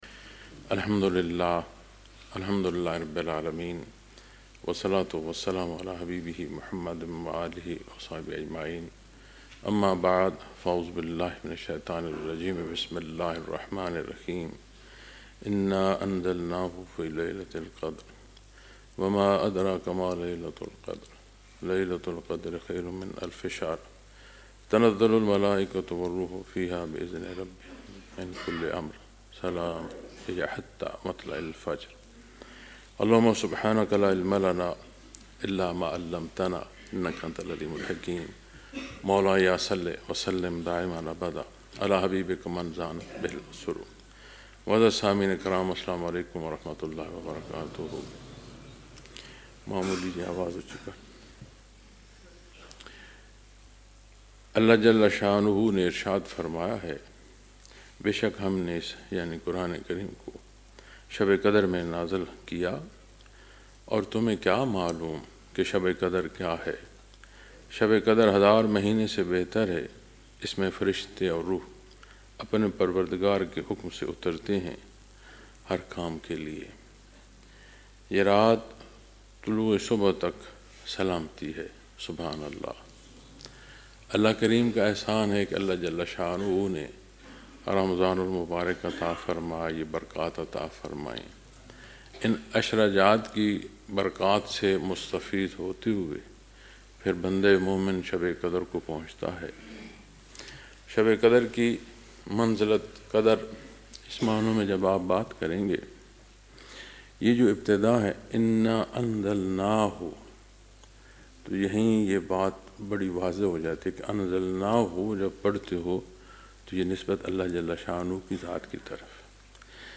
Lectures in Munara, Chakwal, Pakistan on March 13,2026